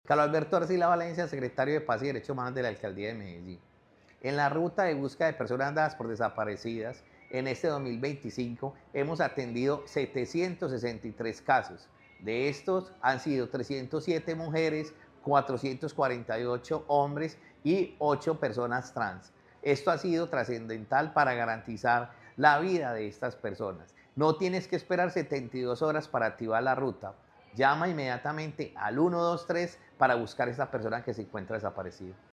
Declaraciones del secretario de Paz y Derechos Humanos, Carlos Alberto Arcila
Declaraciones-del-secretario-de-Paz-y-Derechos-Humanos-Carlos-Alberto-Arcila-1.mp3